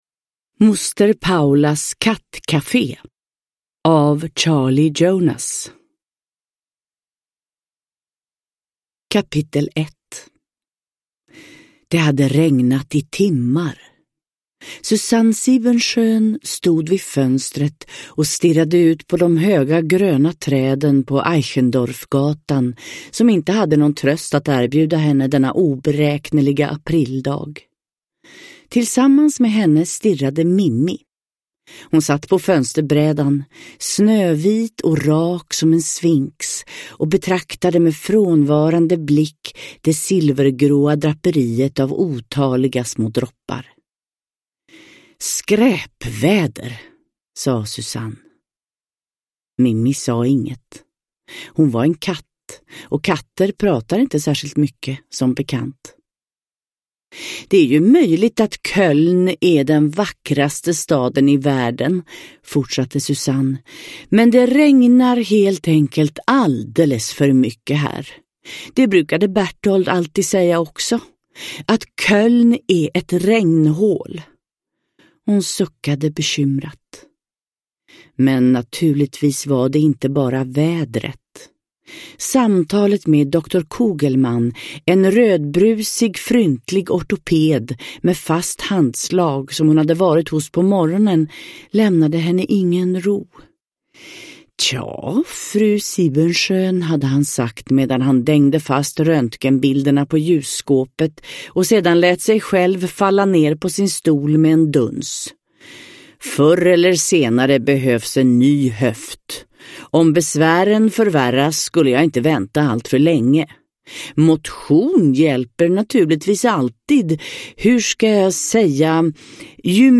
Moster Paulas kattkafé – Ljudbok – Laddas ner